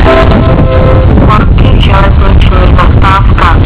Hlášení zastávek a mimořádností:
Běžná hlášení Stáhnout
tabor_horky_zelzast.wav